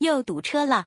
audio_traffic_error.wav